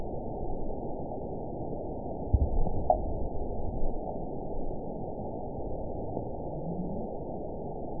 event 917203 date 03/23/23 time 17:20:02 GMT (2 years, 1 month ago) score 9.39 location TSS-AB03 detected by nrw target species NRW annotations +NRW Spectrogram: Frequency (kHz) vs. Time (s) audio not available .wav